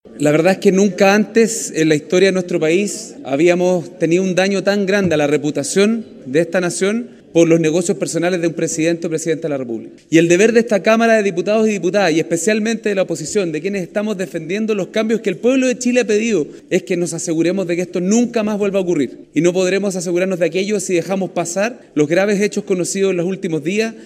El diputado de Nuevo Trato, Pablo Vidal recalcó la unidad de la oposición para presentar el libelo.